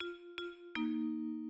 Train melody
The Swiss Federal Railways use three different jingles corresponding to the acronym of the company across three of the four national languages, transposed according to German note-naming conventions, with the final note as a chord. All three are played on the vibraphone:
FFS (Italian) written as "F - F - Es"